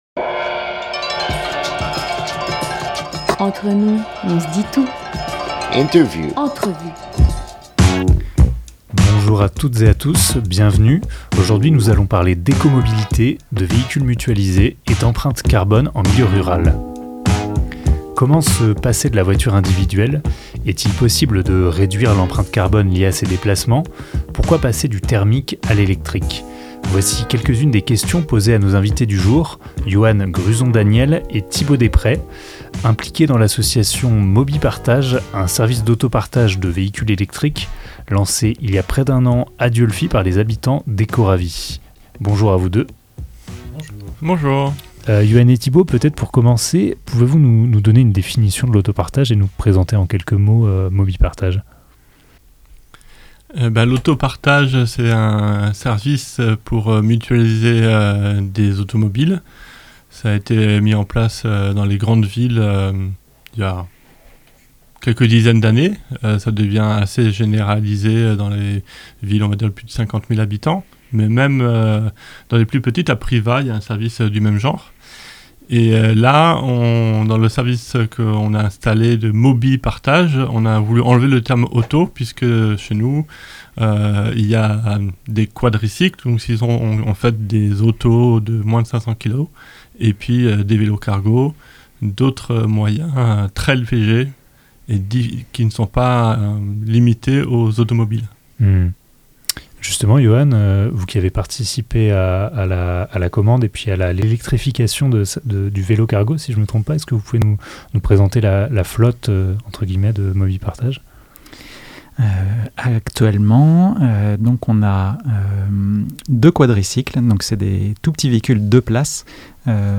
24 octobre 2022 7:00 | Interview